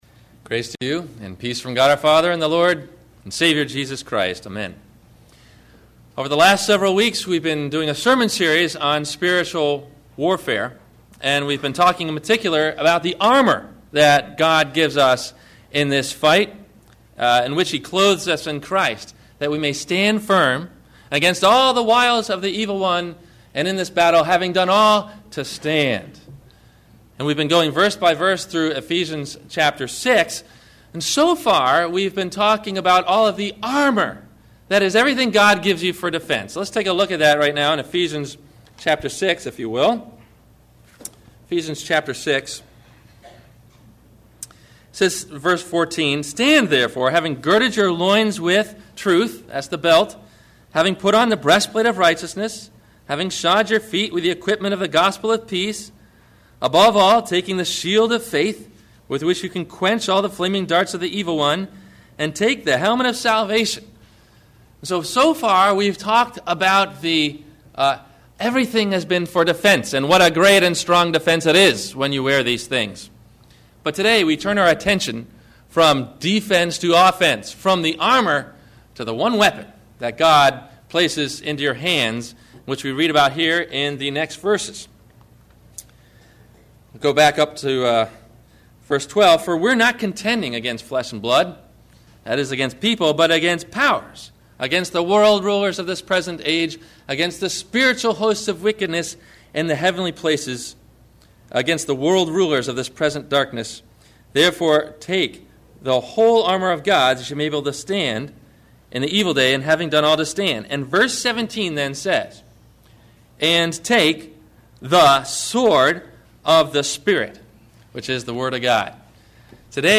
The Sword of the Spirit – Sermon – October 26 2008
Listen for these questions and answers about “The Sword of the Spirit”, in the 1-part MP3 Audio Sermon below.